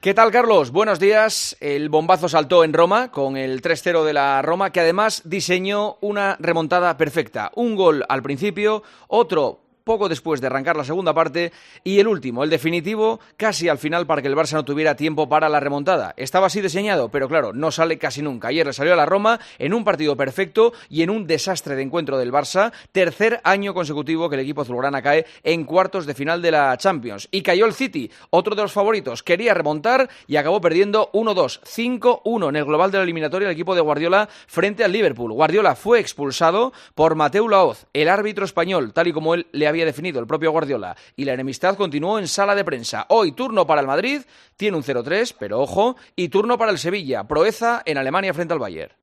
AUDIO: Escucha el comentario del director de 'El Partidazo de COPE', Juanma Castaño, en 'Herrera en COPE'